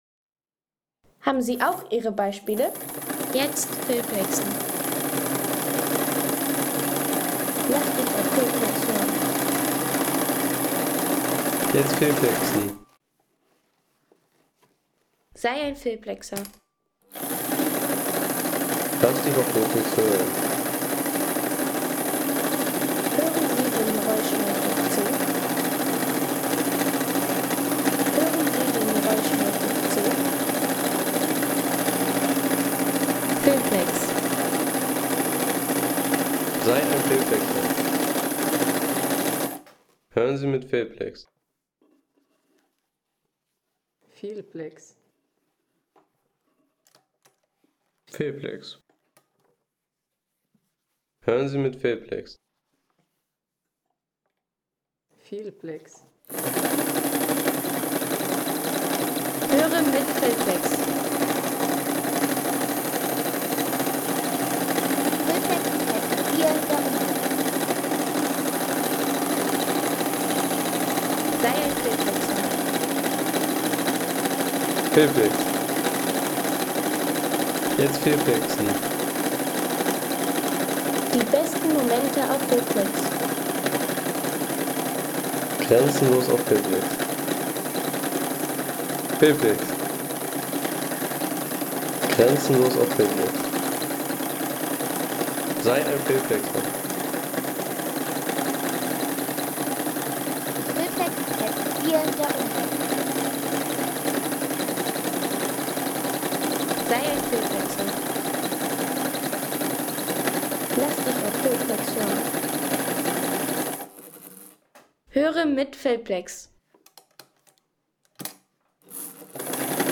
Mercedes de Lux Home Sounds Technik Maschinen Mercedes de Lux Seien Sie der Erste, der dieses Produkt bewertet Artikelnummer: 169 Kategorien: Technik - Maschinen Mercedes de Lux Lade Sound.... Nähmaschine Mercedes de Lux – Nostalgie aus dem Jahr 1960.